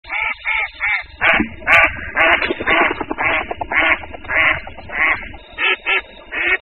Le Canard Souchet